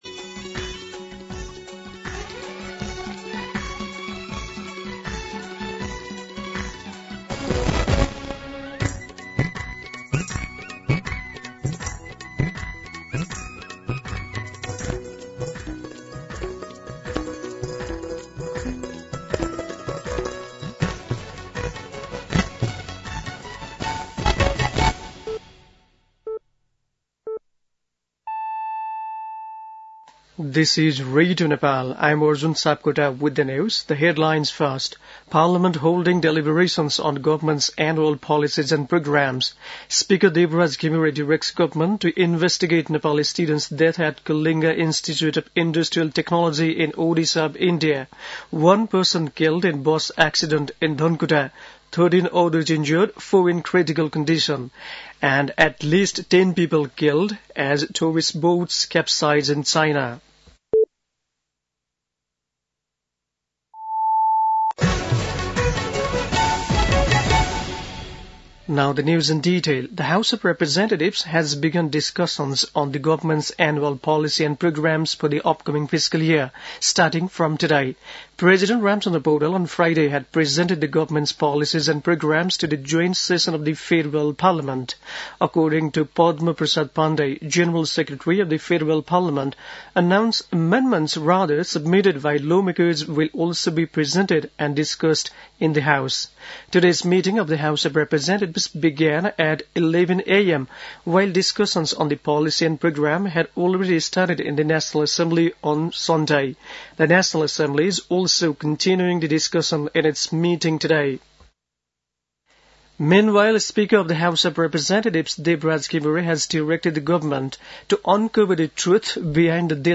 दिउँसो २ बजेको अङ्ग्रेजी समाचार : २२ वैशाख , २०८२
2pm-Nepali-News-1-22.mp3